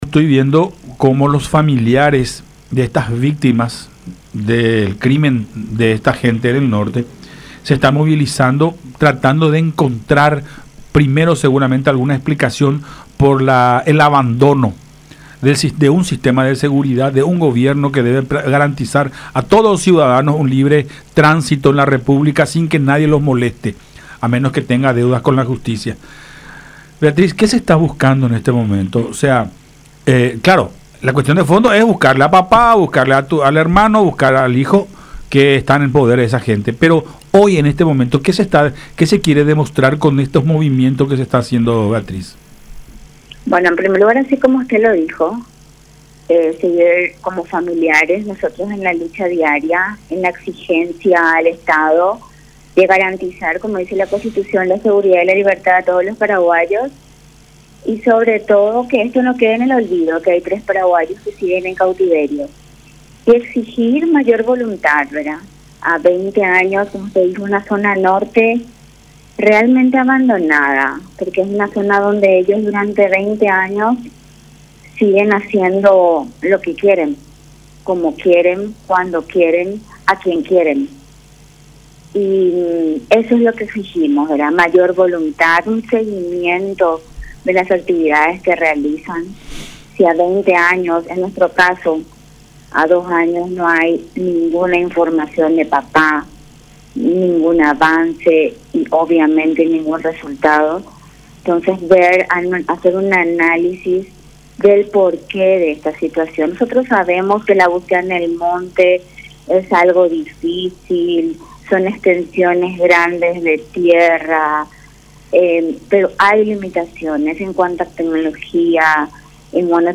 en conversación con Nuestra Mañana por Unión TV y radio La Unión.